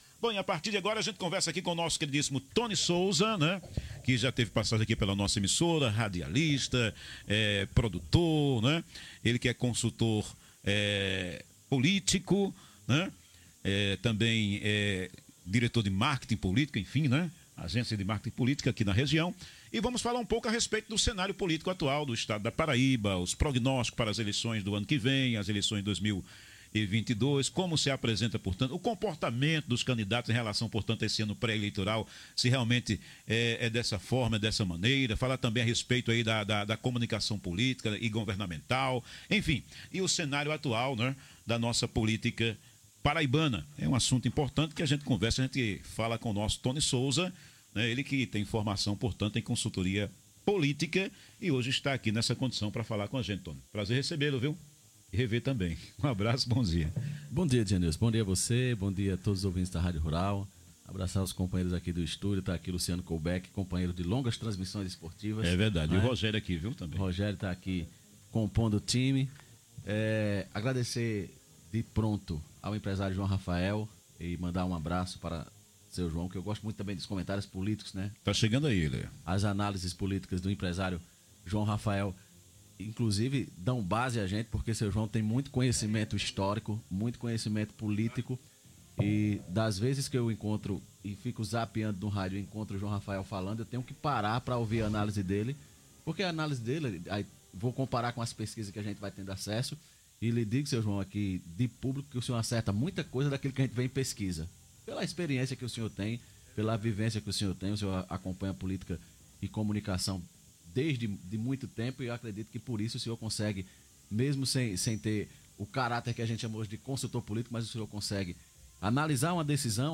concedeu entrevista a Rádio Rural no Jornal 850